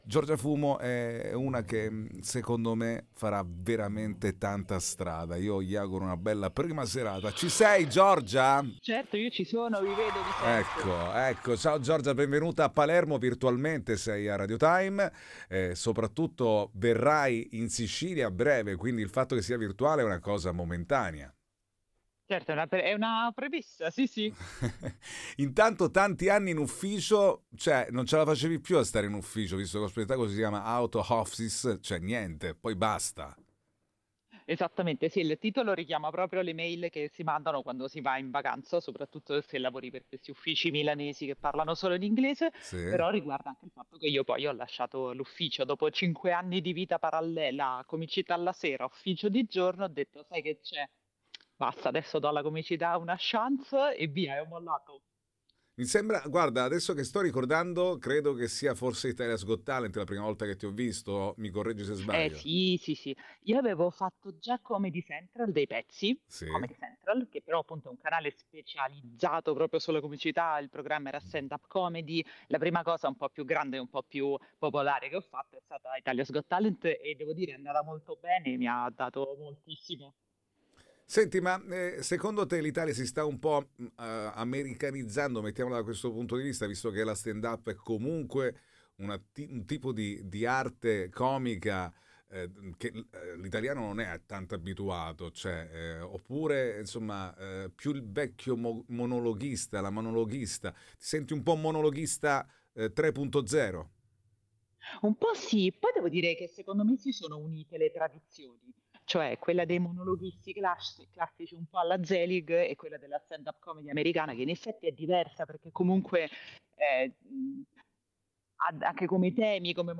INTERVISTA GIORGIA FUMO presenta il suo spettacolo “OUT OF OFFICE”.